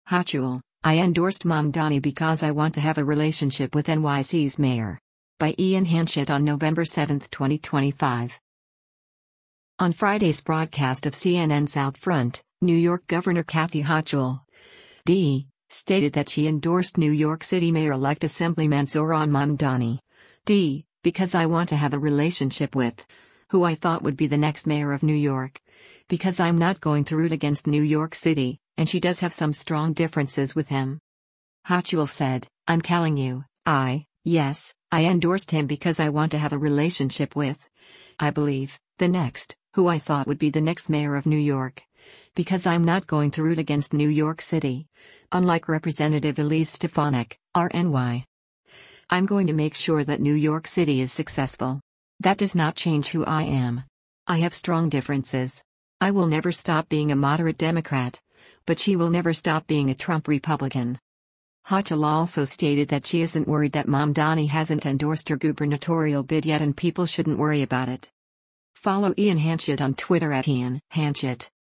On Friday’s broadcast of CNN’s “OutFront,” New York Gov. Kathy Hochul (D) stated that she endorsed New York City Mayor-Elect Assemblyman Zohran Mamdani (D) “because I want to have a relationship with…who I thought would be the next mayor of New York, because I’m not going to root against New York City,” and she does have some “strong differences” with him.